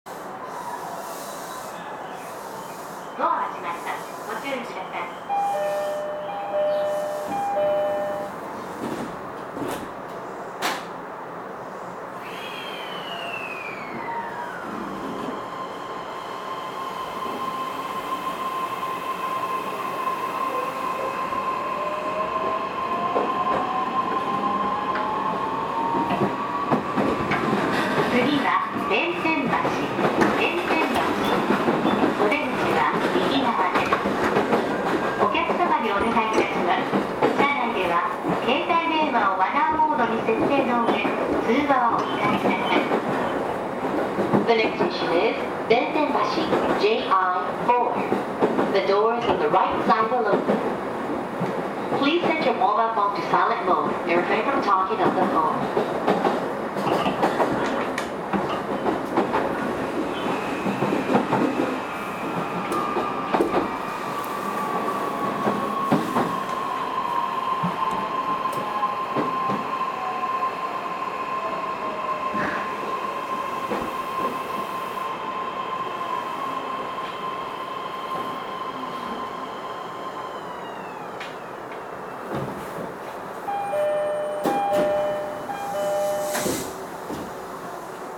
走行音